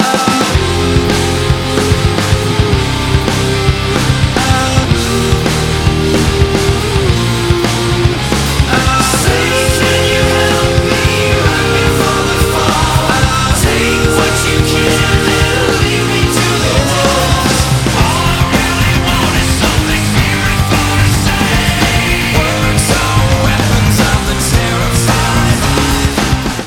мужской вокал
nu metal
Alternative Rock
post-grunge